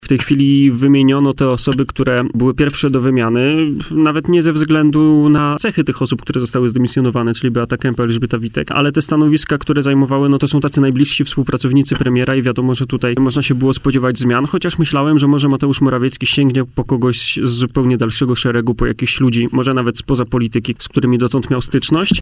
w poranku „Siódma9” na antenie Radia Warszawa